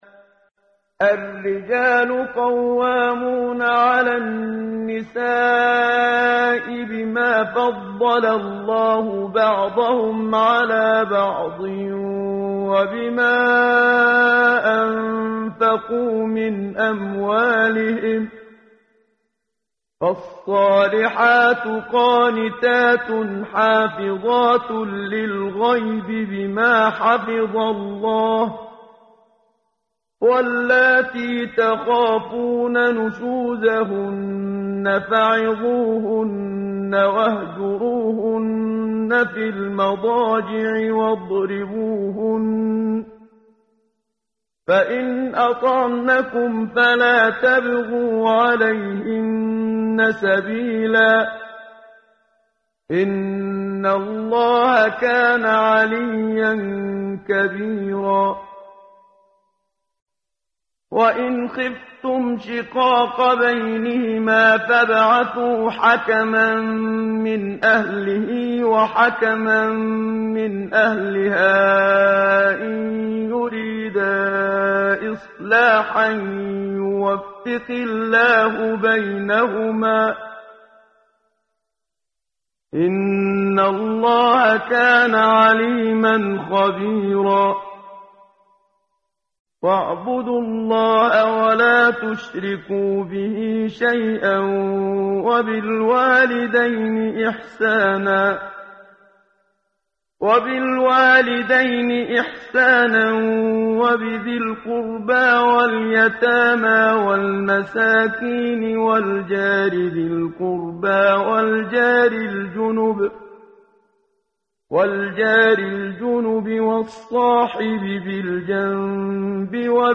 ترتیل صفحه 84 سوره مبارکه سوره نساء (جزء پنجم) از سری مجموعه صفحه ای از نور با صدای استاد محمد صدیق منشاوی